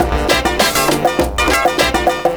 Index of /90_sSampleCDs/USB Soundscan vol.46 - 70_s Breakbeats [AKAI] 1CD/Partition A/13-100SOUND4